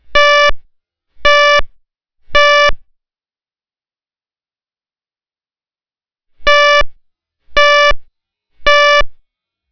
Provides up to 85 dB at 5 feet.
3_pulse_horn.wav